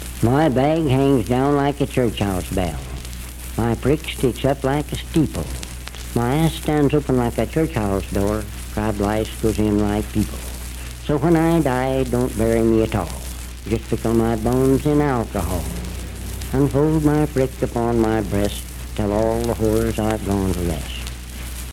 Unaccompanied vocal music
Verse-refrain 2(4). Performed in Sandyville, Jackson County, WV.
Bawdy Songs, Folklore--Non Musical
Voice (sung)